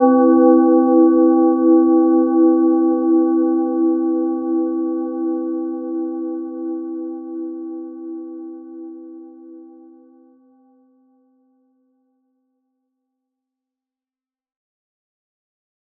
Gentle-Metallic-2-E4-mf.wav